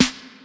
• Loud Snare Single Hit C Key 29.wav
Royality free snare one shot tuned to the C note. Loudest frequency: 3152Hz
loud-snare-single-hit-c-key-29-fwK.wav